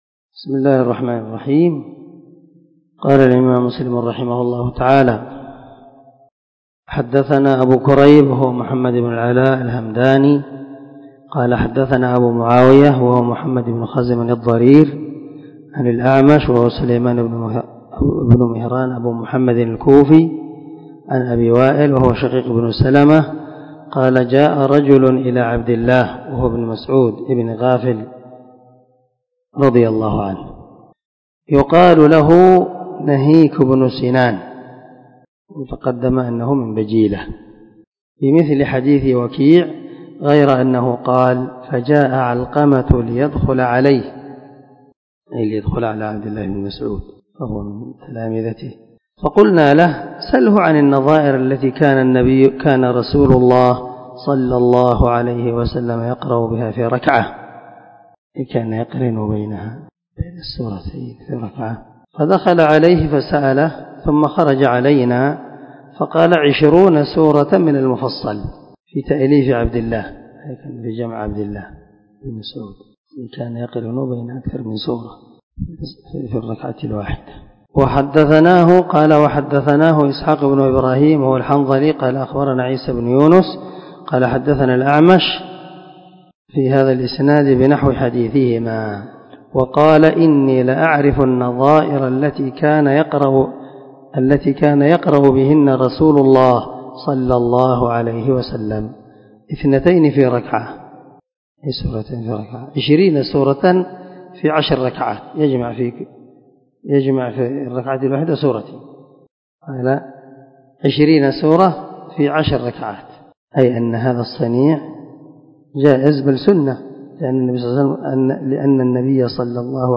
500الدرس 68من شرح كتاب صلاة المسافر وقصرها تابع حديث رقم ( 822 ) من صحيح مسلم
دار الحديث- المَحاوِلة- الصبيحة.